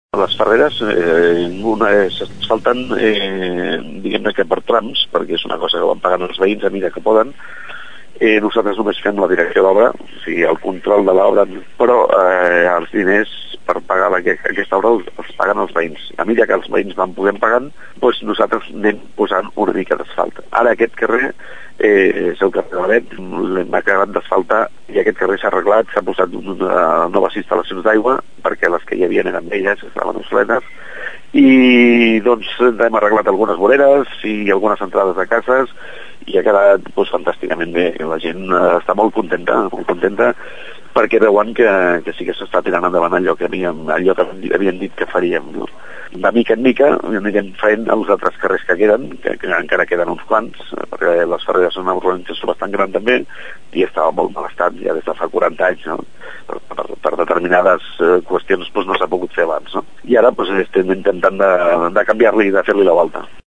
En aquesta urbanització són els veïns els que assumeixen el cost d’asfaltar els carrers, de manera que es va fent a poc a poc, en la mesura que poden econòmicament. Escoltem el regidor d’Obres i Serveis de l’Ajuntament de Tordera, Carles Seijo.